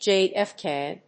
/ˌdʒeˌɛˈfke(米国英語), ˌdʒeɪˌeˈfkeɪ(英国英語)/